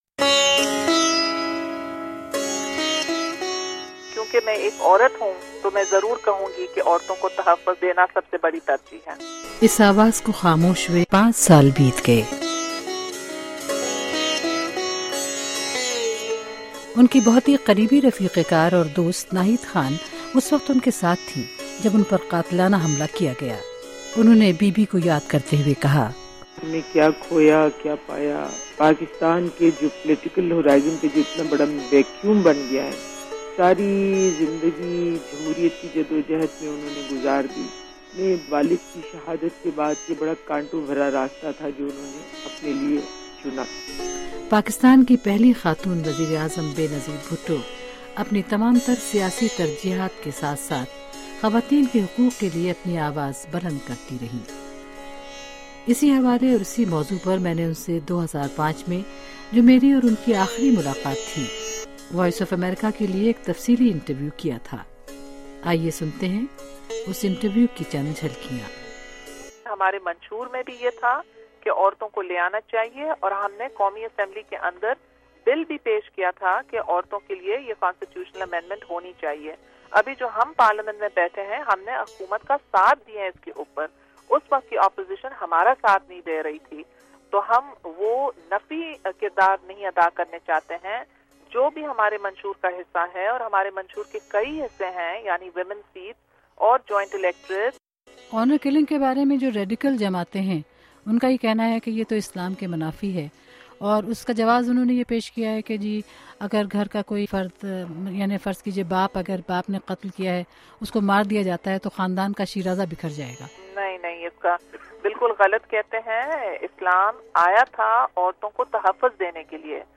سنہ 2005میں ’وائس آف امریکہ‘ کو دیے گئے ایک انٹرویو میں اُنھوں نے کہا تھا کہ پیپلز پارٹی کے منشور میں ہے کہ ہم عورتوں کے حقوق کا تحفظ کریں گے۔